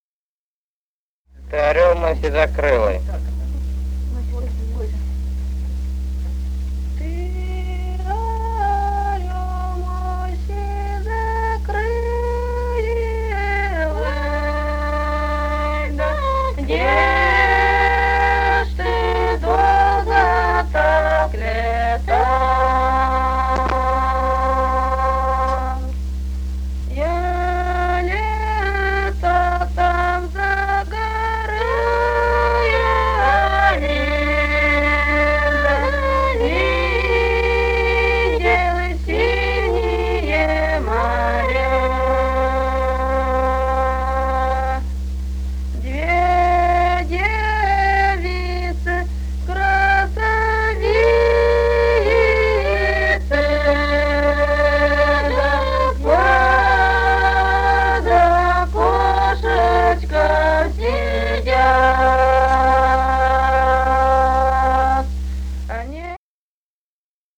Русские народные песни Красноярского края.
«Ты орёл мой сизокрылый» (лирическая). с. Подгорное Енисейского района.